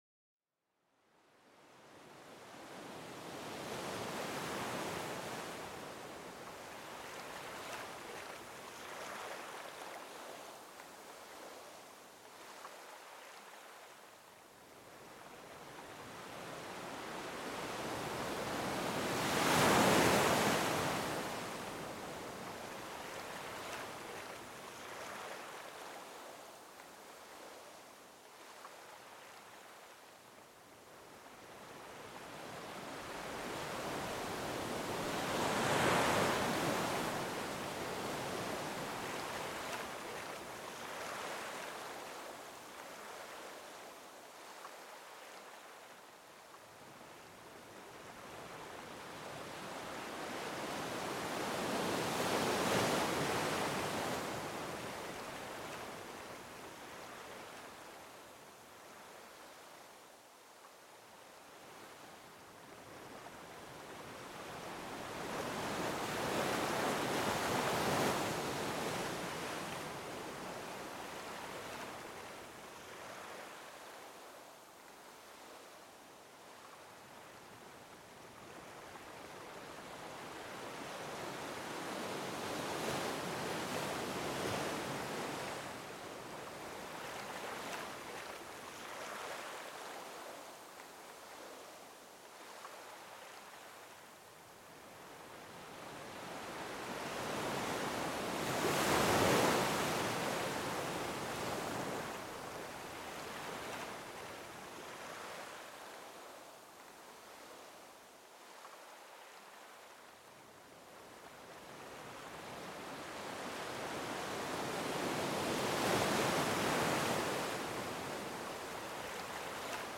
Las olas relajantes del océano para una mente tranquila